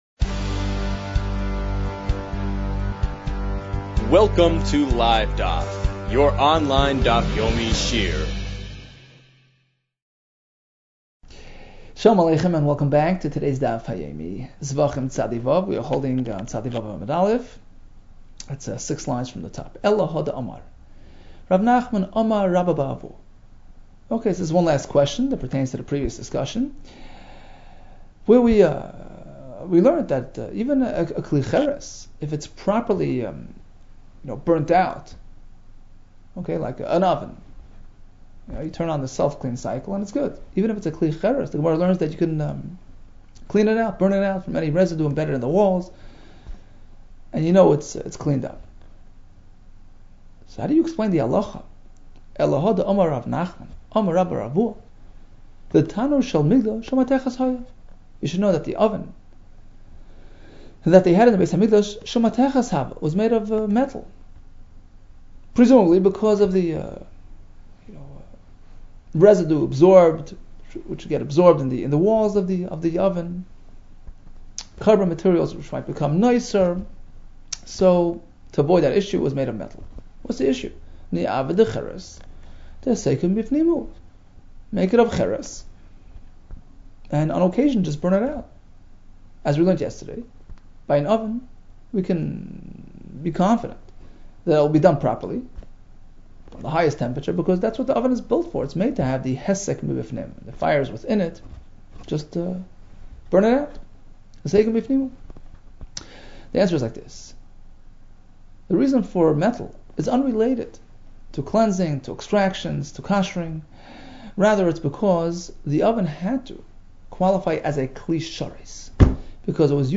Zevachim 96 - זבחים צו | Daf Yomi Online Shiur | Livedaf